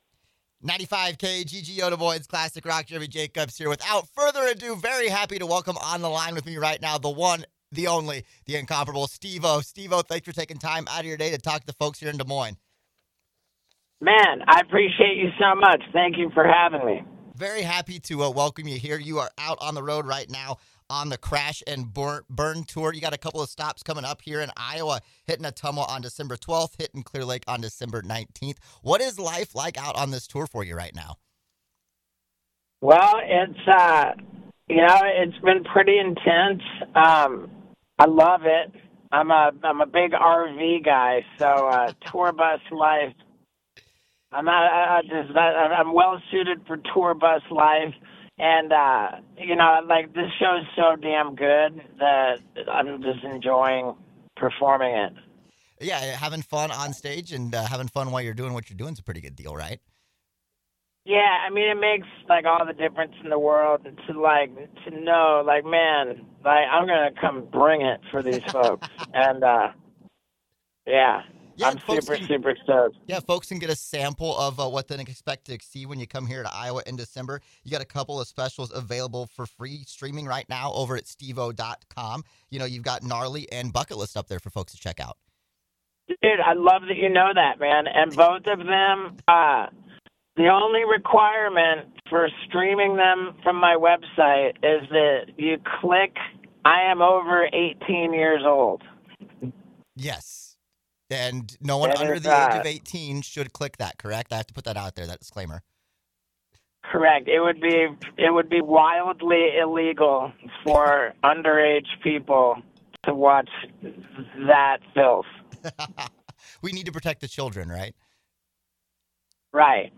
Steve-O Interview